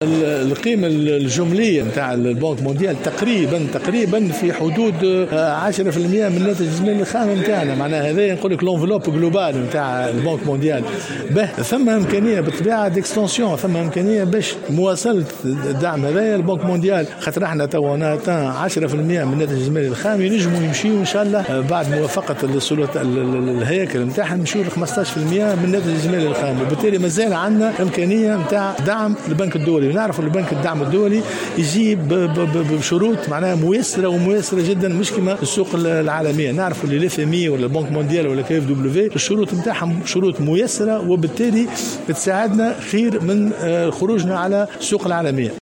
أكد مقرر لجنة المالية فيصل دربال في تصريح لمراسل الجوهرة "اف ام" اليوم الإثنين أن قيمة تمويل البنك الدولي تقدر ب 10% من الناتج القومي الخام.